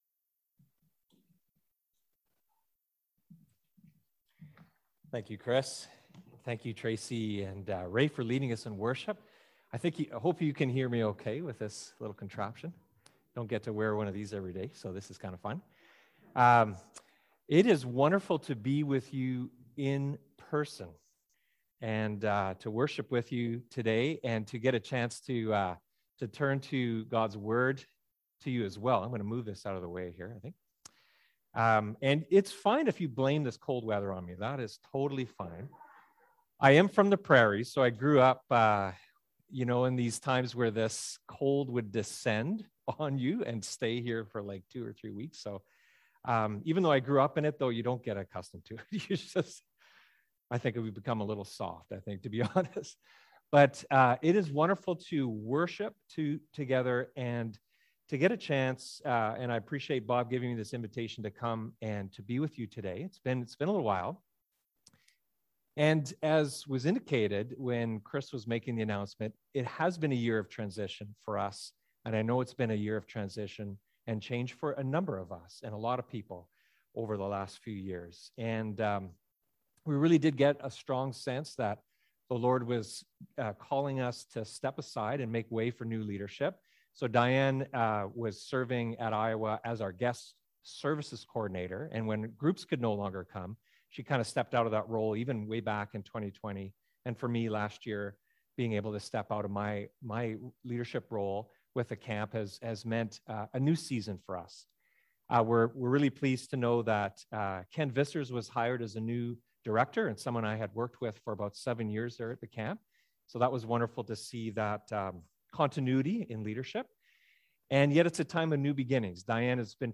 1 Corinthians 15:12-20 Service Type: Sermon